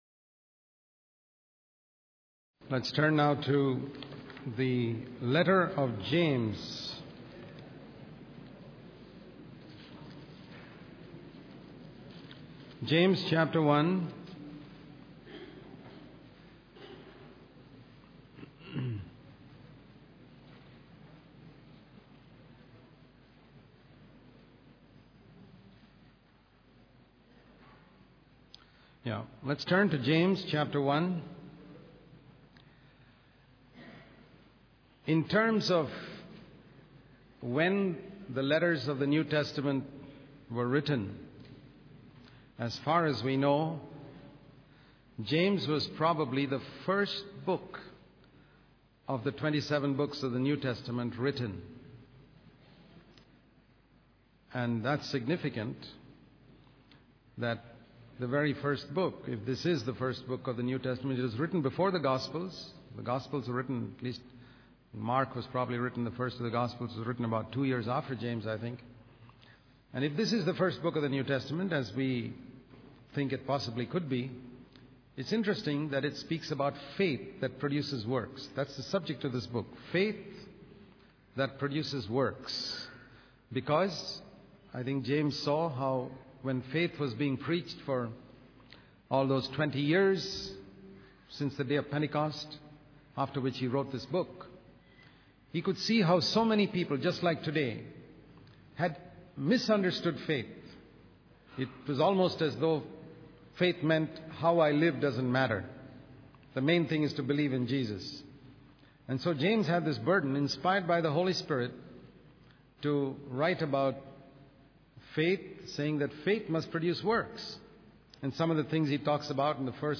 In this sermon, the preacher emphasizes the importance of faith and its connection to producing good works. He highlights various aspects of faith discussed in the book of James, such as overcoming temptation, showing love to all, controlling one's speech, and cultivating purity and patience.